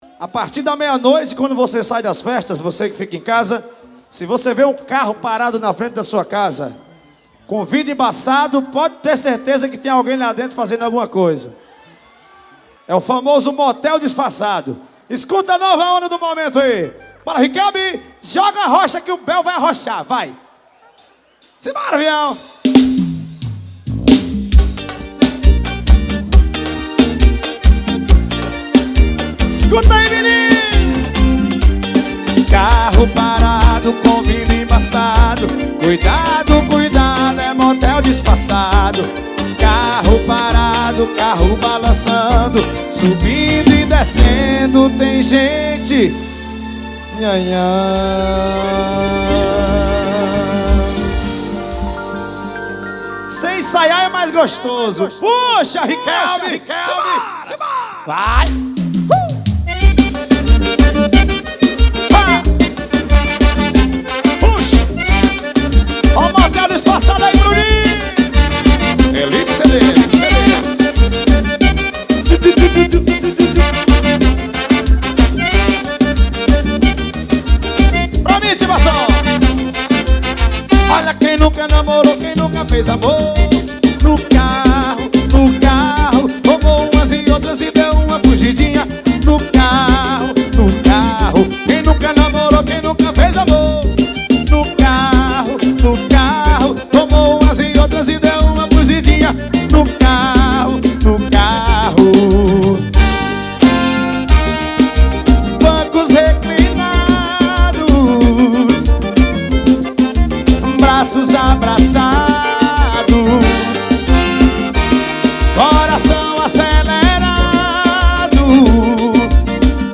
2024-12-30 10:45:05 Gênero: Forró Views